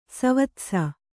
♪ savatsa